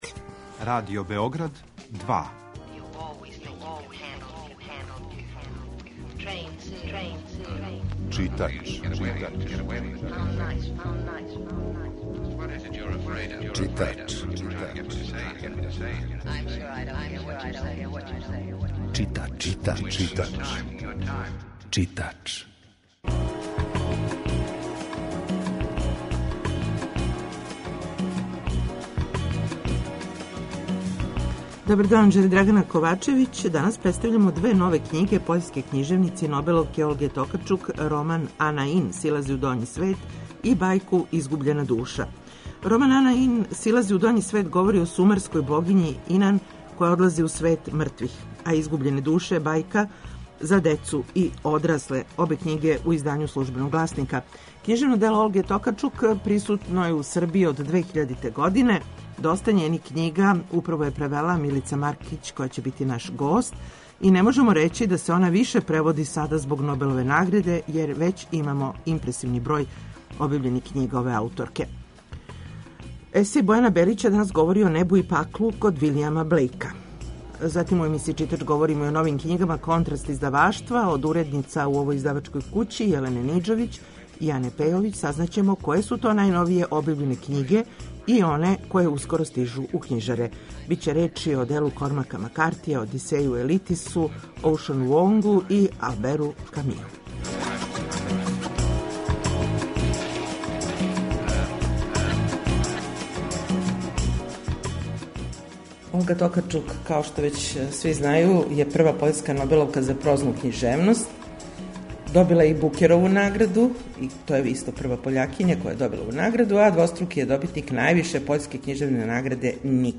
Емисија је колажног типа, али је њена основна концепција – прича о светској књижевности